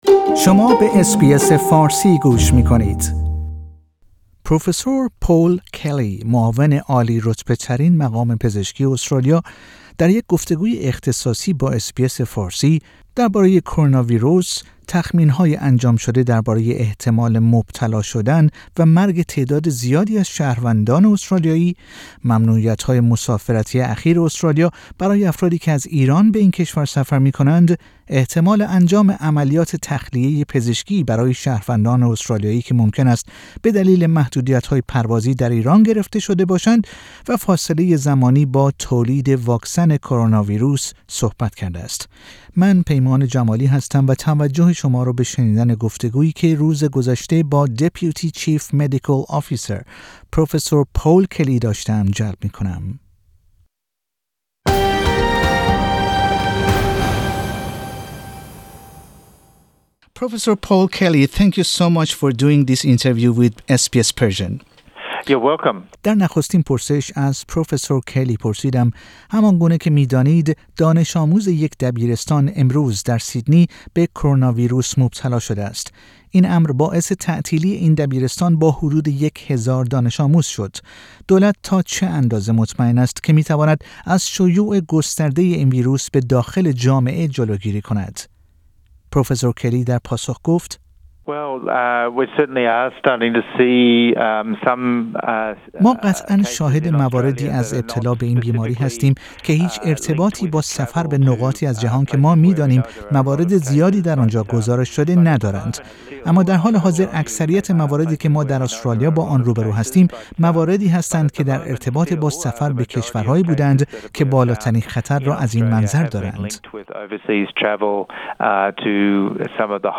پروفسور پل کلی معاون عالی رتبه ترین مقام پزشکی استرالیا (Deputy Chief Mediacl Officer) در یک گفتگوی اختصاصی با اس بی اس فارسی درباره کروناویروس، تخمین های انجام شده درباره احتمال مبتلا شدن و مرگ تعداد زیادی از شهروندان استرالیایی، ممنوعیت های مسافرتی اخیر استرالیا برای افرادی که از ایران به این کشور سفر می کنند، احتمال انجام عملیات تخلیه پزشکی برای شهروندان استرالیایی که ممکن است به دلیل محدودیت های پروازی در ایران گرفتار شده باشند و دریچه زمانی با تولید واکسن کروناویروس (کووید-۱۹) صحبت کرده است.